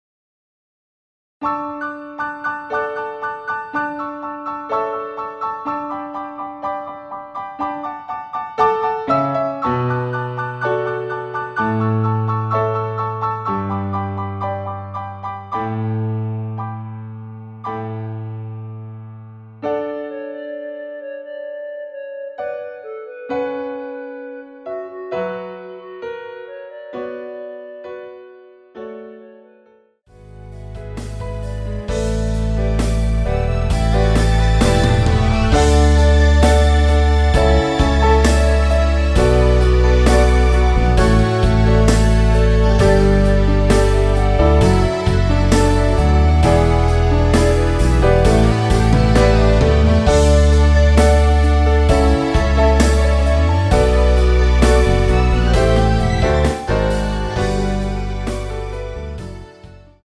원키에서(-4)내린 멜로디 포함된 MR입니다.
앞부분30초, 뒷부분30초씩 편집해서 올려 드리고 있습니다.
중간에 음이 끈어지고 다시 나오는 이유는